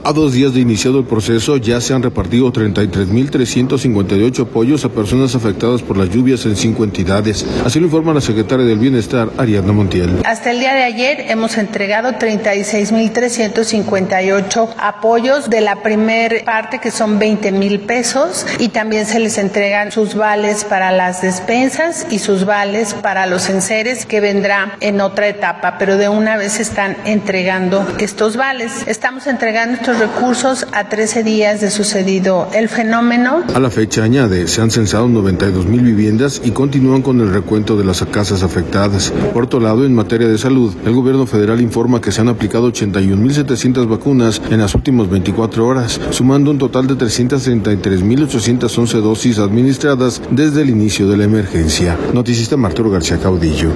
A dos días de iniciado el proceso, ya se han repartido 33 mil 358 apoyos a personas afectadas por las lluvias en cinco entidades, así lo informa la secretaria del Bienestar, Ariadna Montiel.